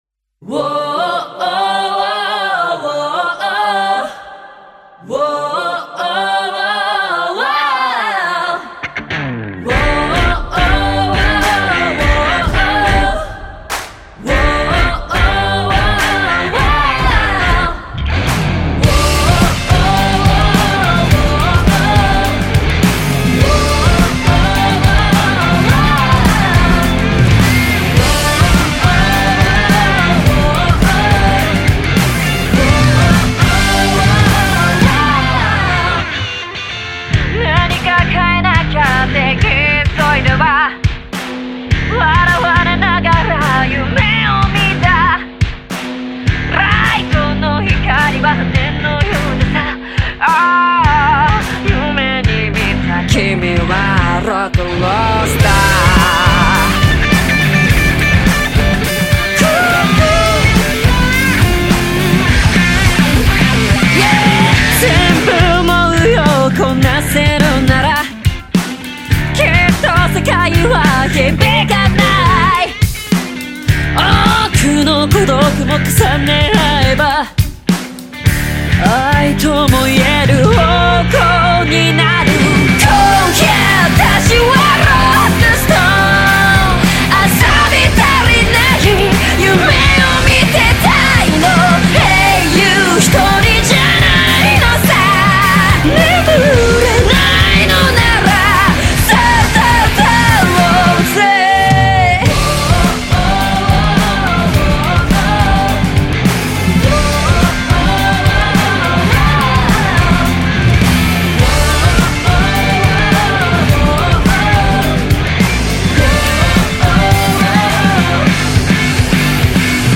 Label JPop